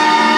Choir Hit.wav